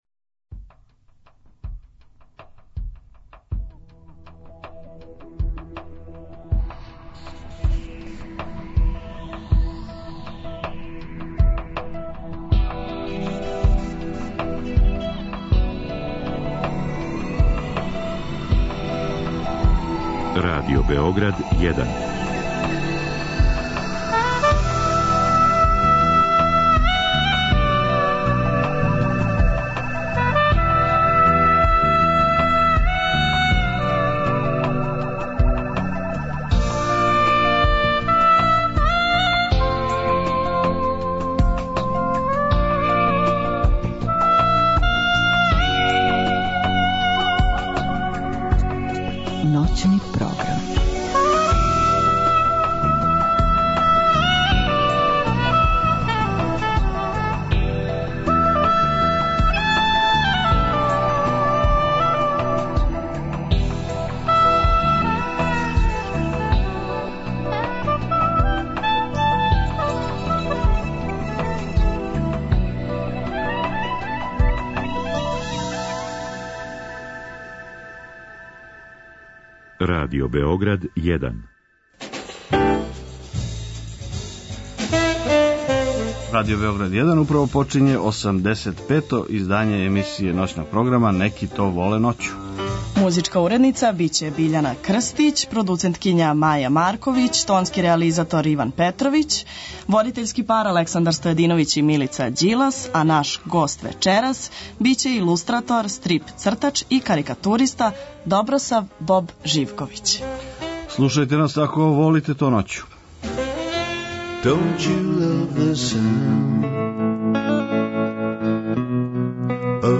Наш гост је један од најзначајнијих српских илустратора, стрип-цртача и карикатуриста, Добросав Боб Живковић. Разговараћемо о књигама, адвертајзингу, антиратном активизму...